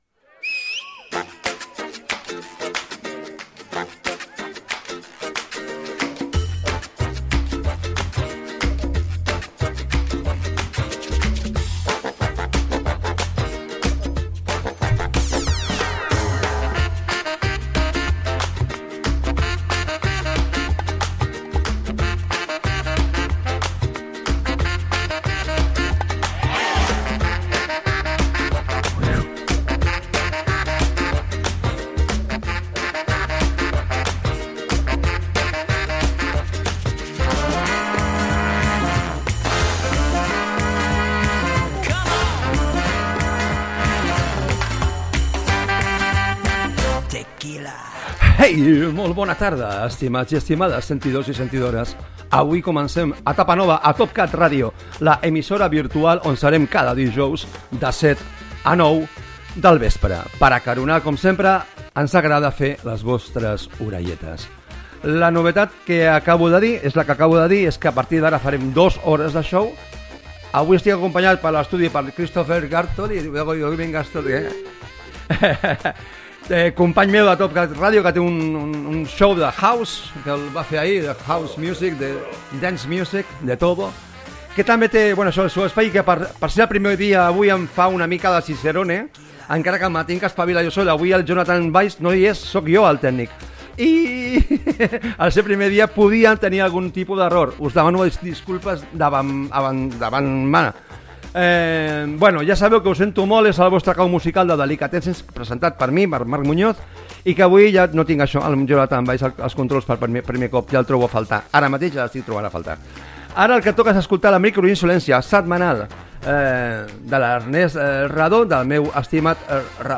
Presentació de la primera edició del programa a Topcat Radio, tema musical
Musical